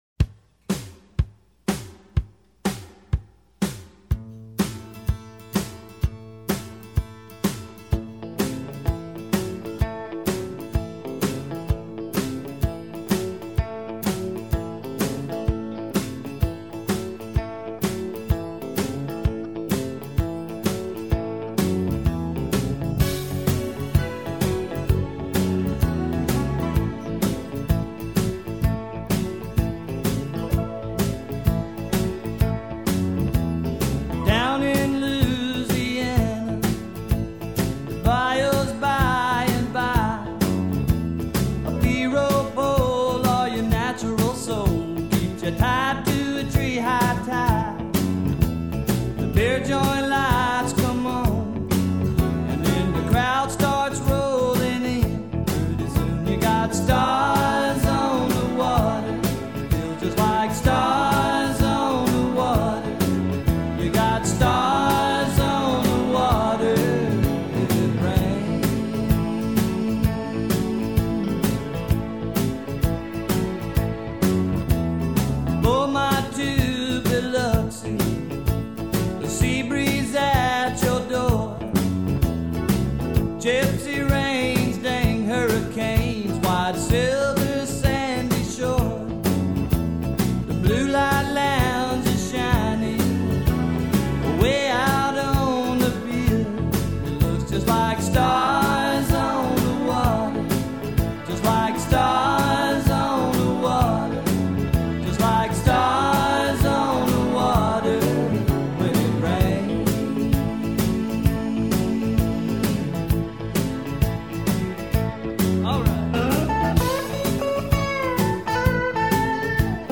key of A